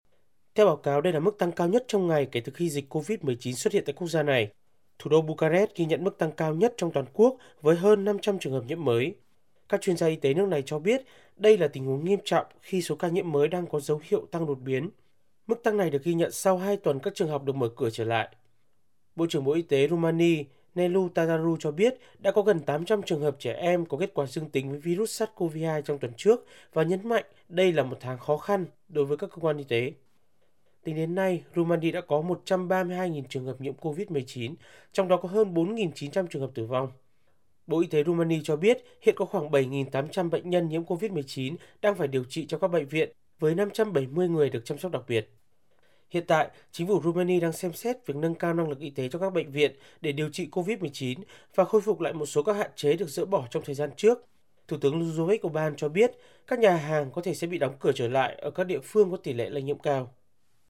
THỜI SỰ Tin thời sự